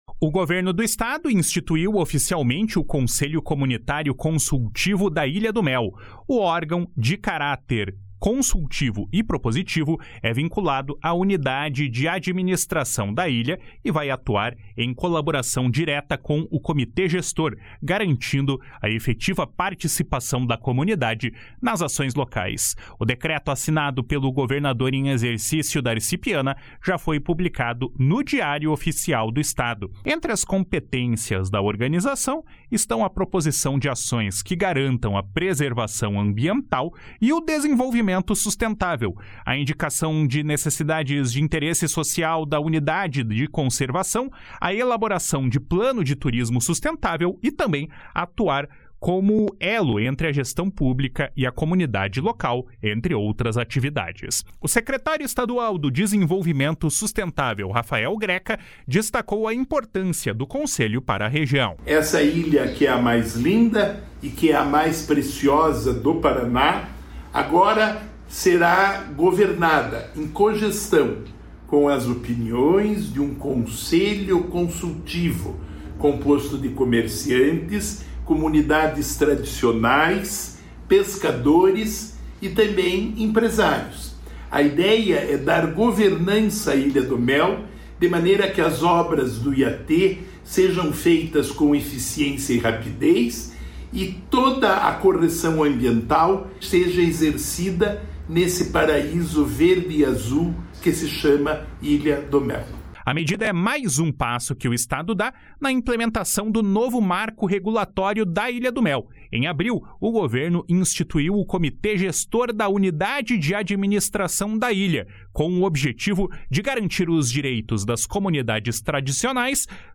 O secretário estadual do Desenvolvimento Sustentável, Rafael Greca, destacou a importância desse Conselho para a região. // SONORA RAFAEL GRECA //